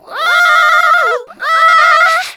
SCREAM2   -L.wav